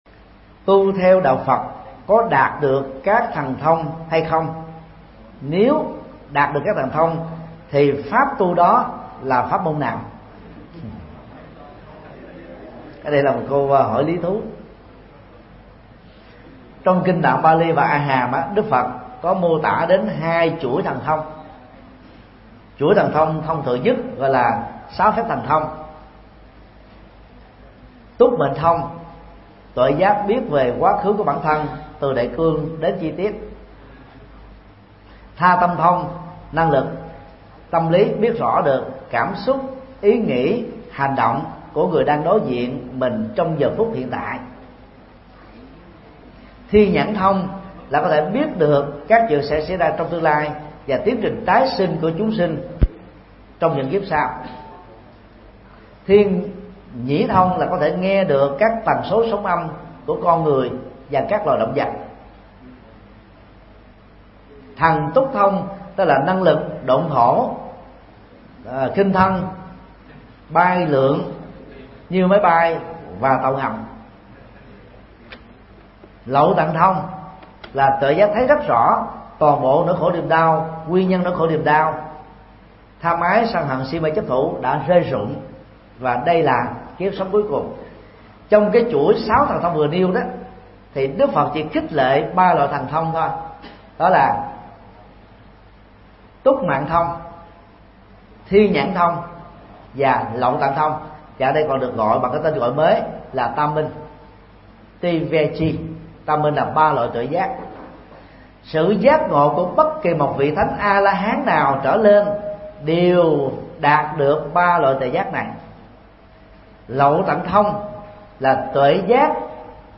Vấn đáp: Ý nghĩa thần thông trong Phật giáo